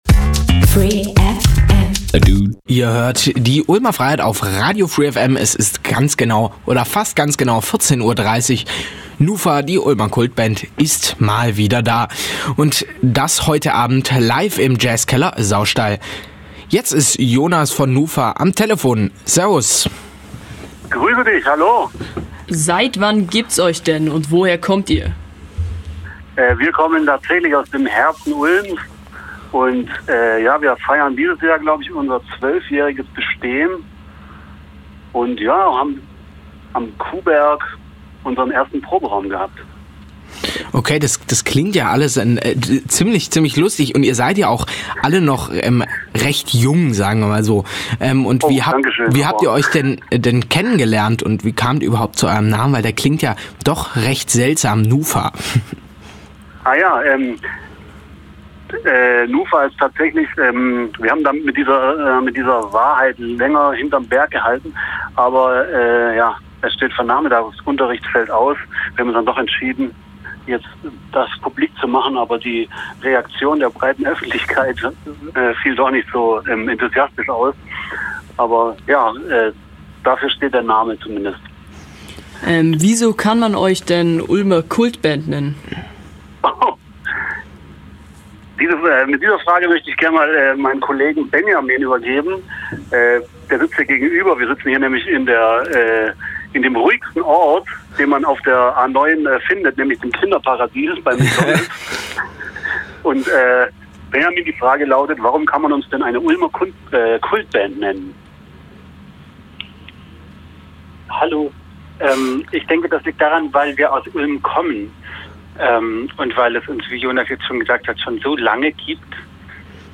Heute war die Kultband Nufa per Telefon in der Ulmer Freiheit zugeschaltet.
Radio